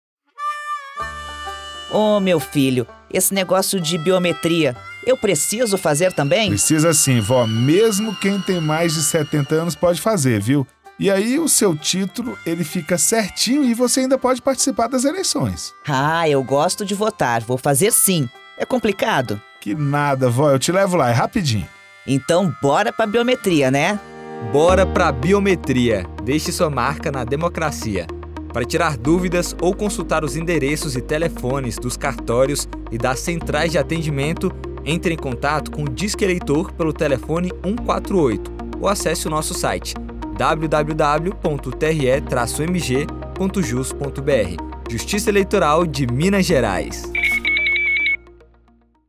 TRE-MG DIALOGO IDOSA E NETO